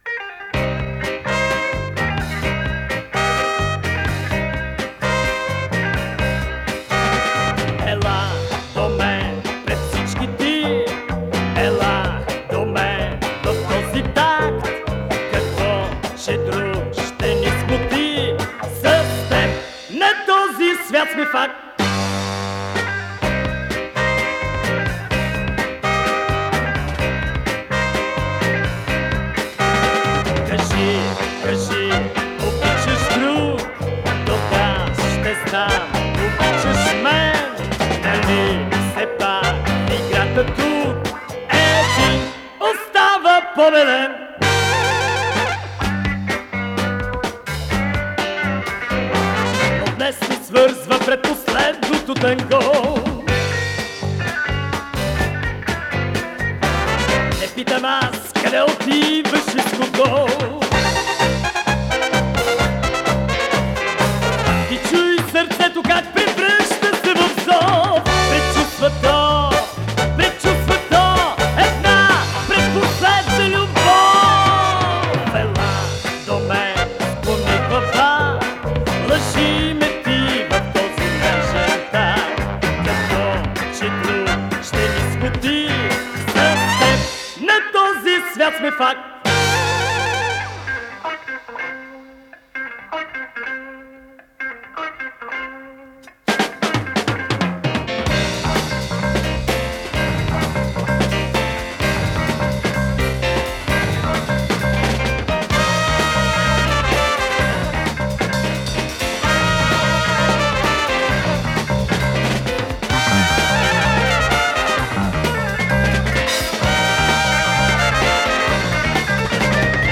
с профессиональной магнитной ленты
Композиторыиспанская мелодия
ВариантДубль моно